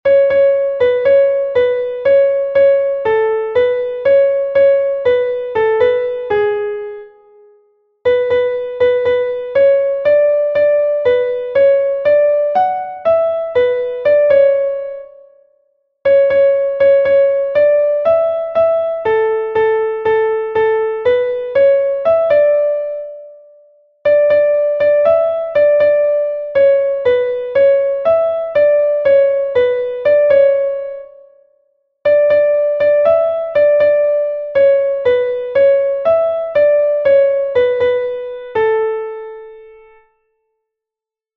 Kontakizunezkoa